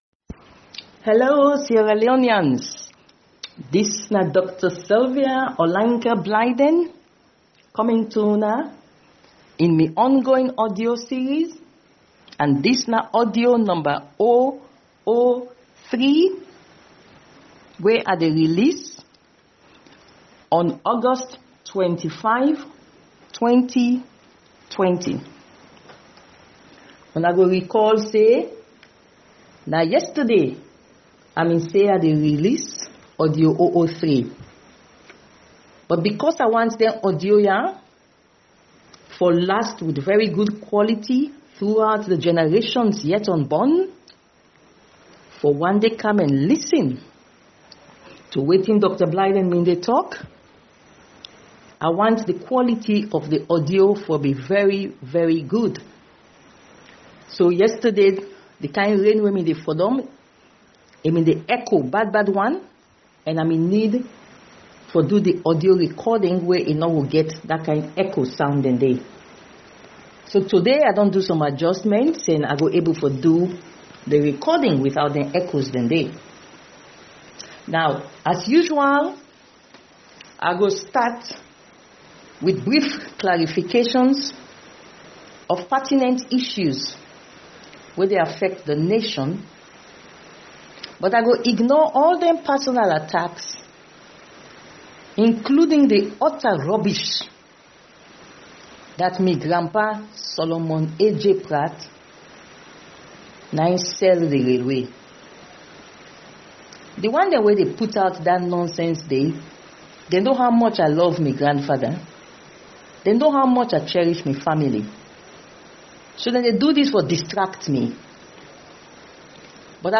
The dialogue with Sierra Leoneans by Dr. Sylvia Olayinka Blyden continues with the release of her Audio-003 recorded message yesterday. In this her third Audio message, she explains her desire for her Audios to last the test of time for generations yet unborn to listen and learn about what she stood for at this time in Sierra Leone.